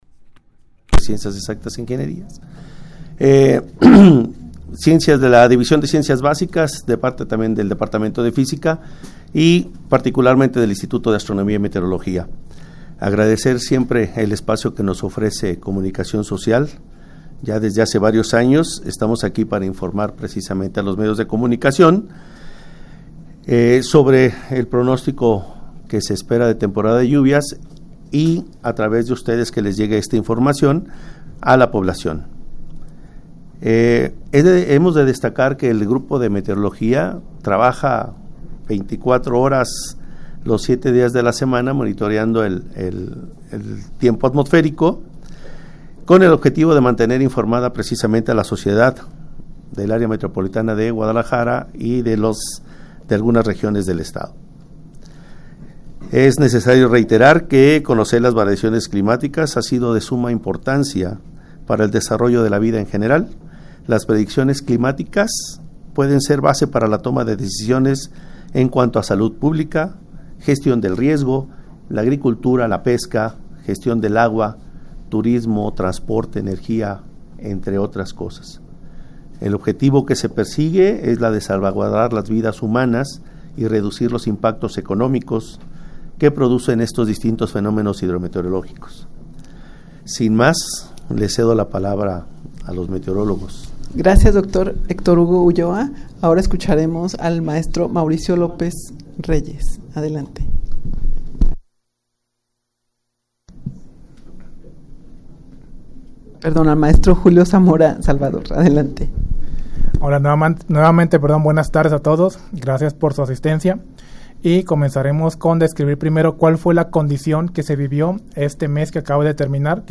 rueda-de-prensa-para-dar-a-conocer-el-pronostico-de-clima-de-junio.mp3